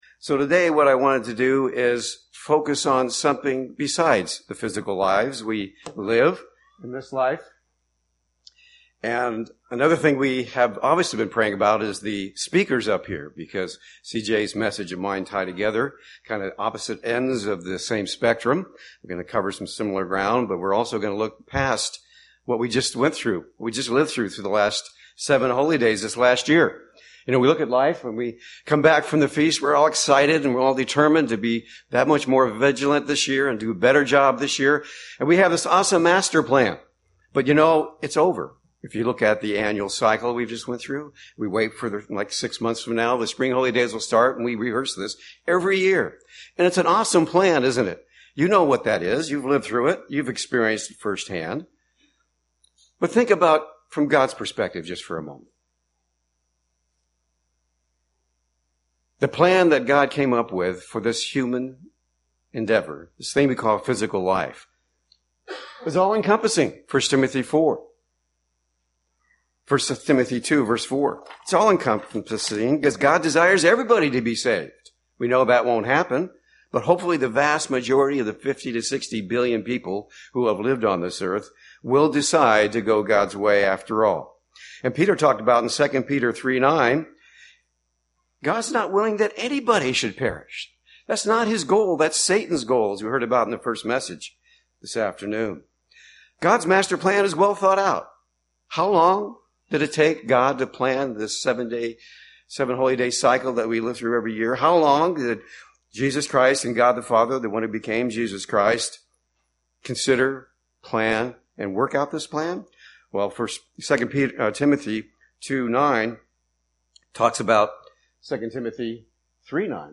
Given in Seattle, WA
UCG Sermon Studying the bible?